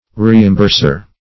Reimburser \Re`im*burs"er\ (-b?rs"?r), n. One who reimburses.